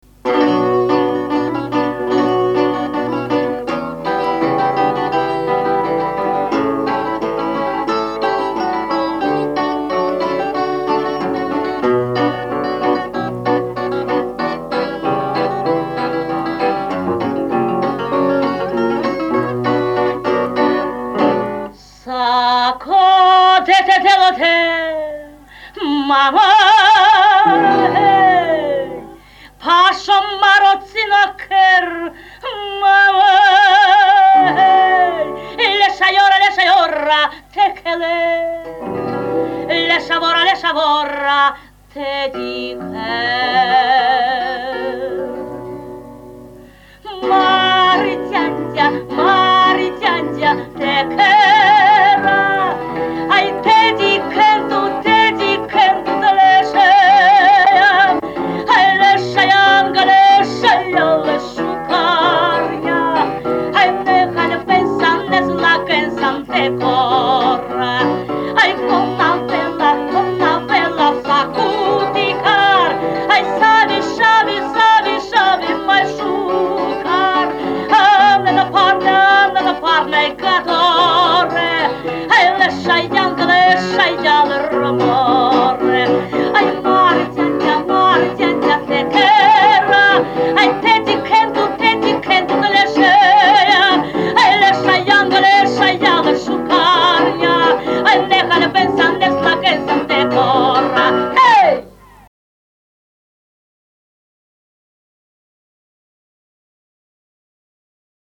Мар, дяндя - известная народная цыганская песня.